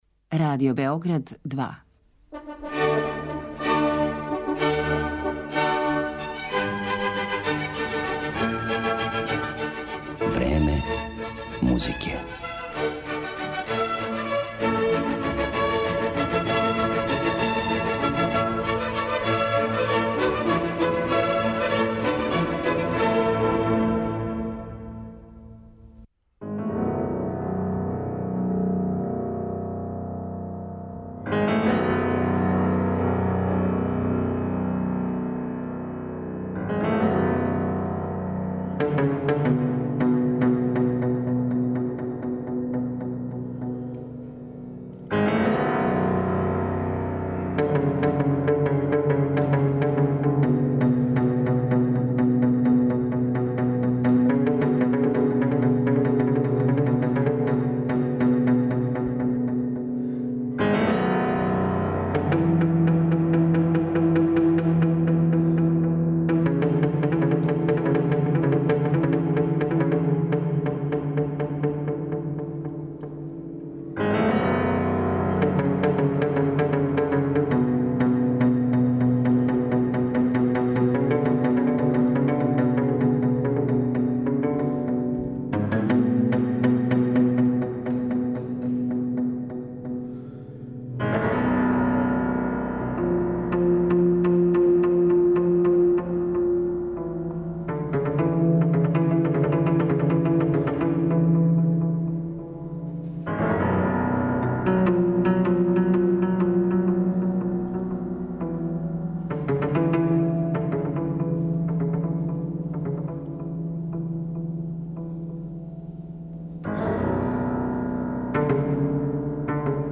Осим података из његове уметничке биографије и детаља из необичне извођачке естетике, емитоваћемо и Сајева тумачења клавирских соната Хајдна и Бетовена, као и његове властите композиције и импровизације.